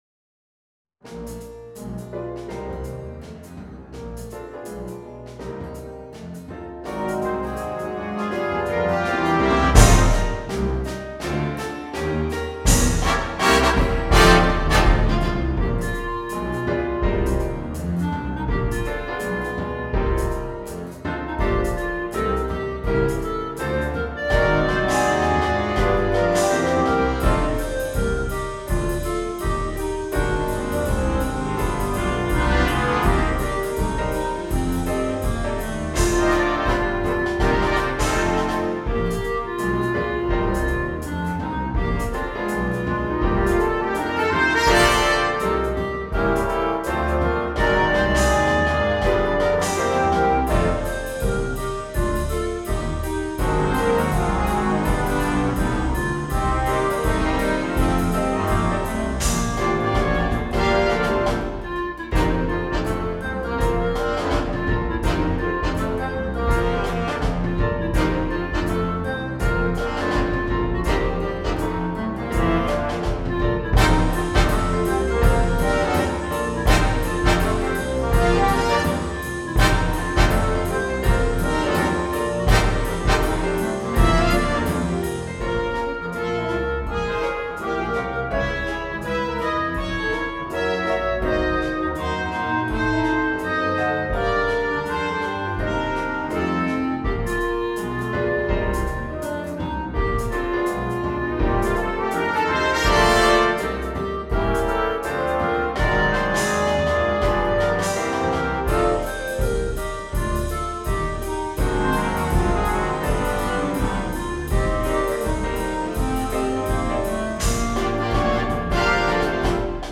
arranged for Bb clarinet and Big Band.